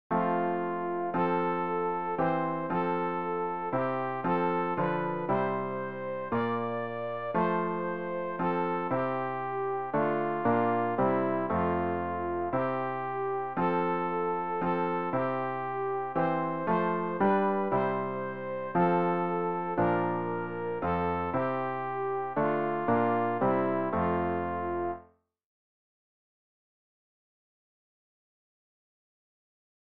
sopran-rg-156-herr-jesu-christ-dich-zu-uns-wend.mp3